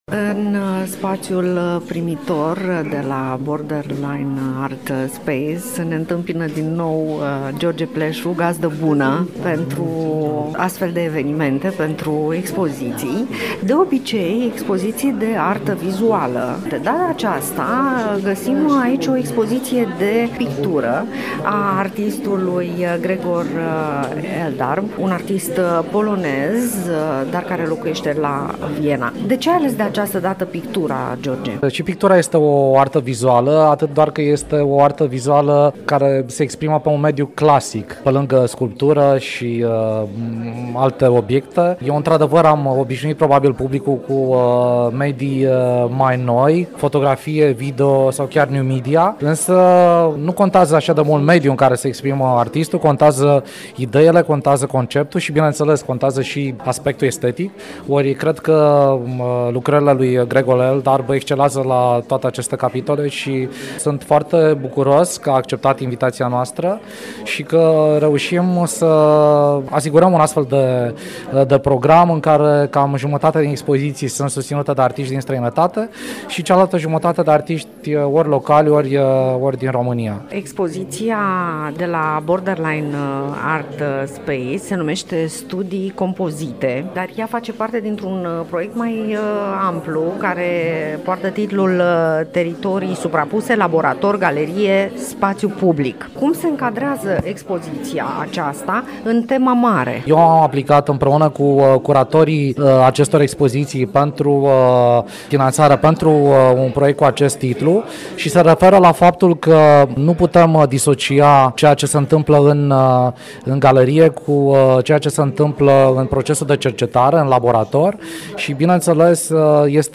(AUDIO) Borderline Art Space din Iași ne invită la pictură - INTERVIU